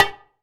9HIBONGO.wav